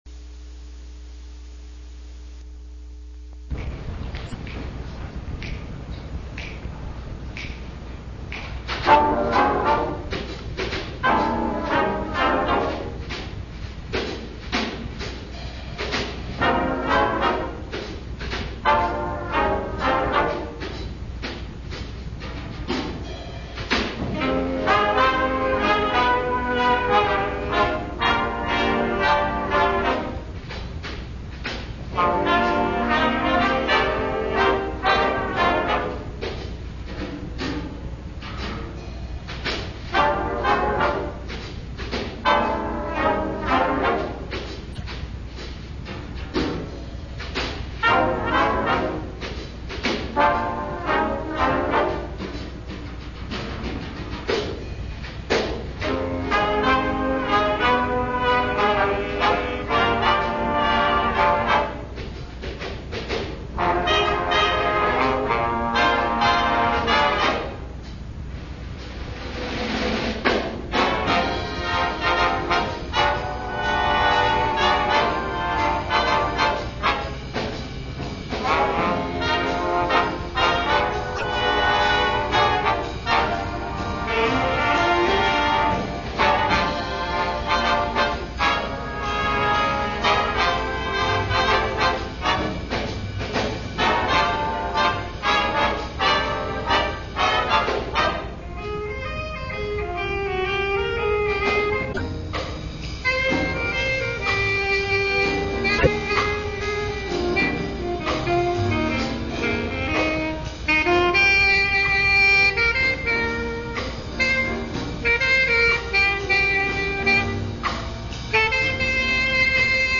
The only recording I have is an 1984 recording of Perry High School Jazz Band at the Iowa Jazz Championships.
Perryjazzband3rdInState.wav